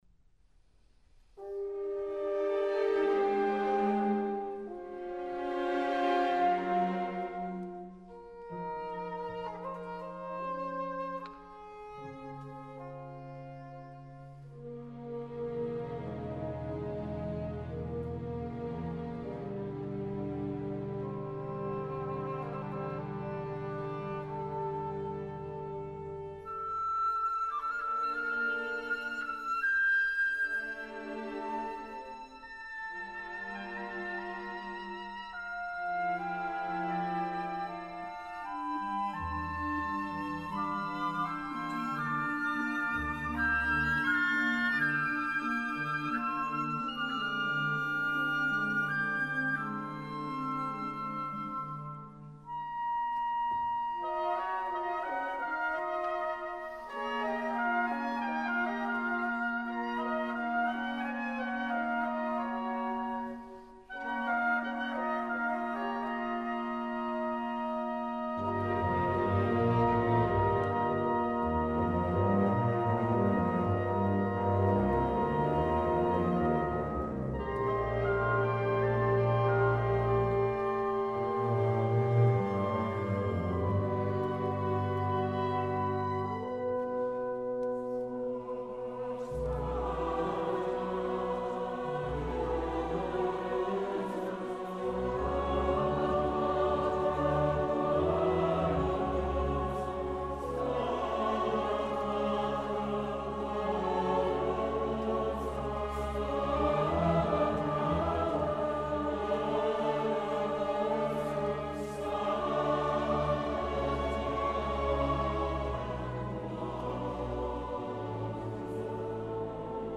2. Orchester, Chor T. 302-372: